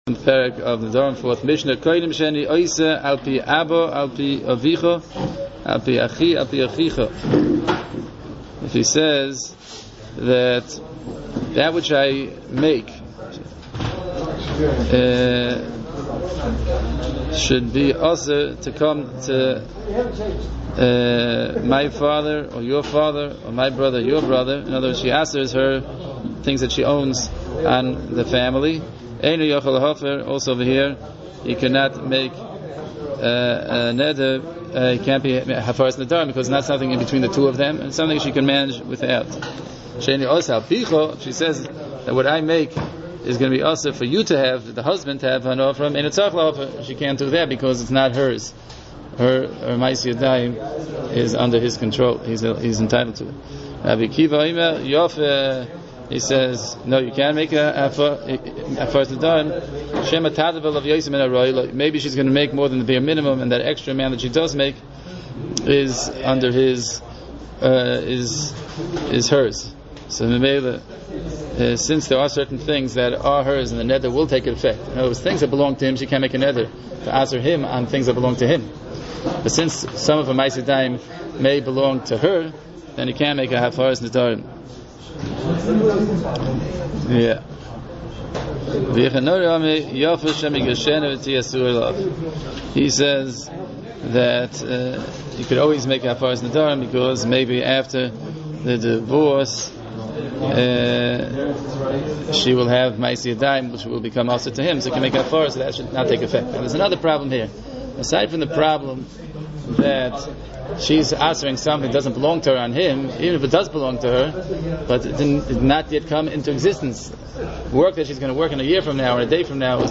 Hear the Mishnah and its Halachos